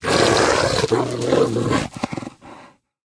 pdog_aggression_0.ogg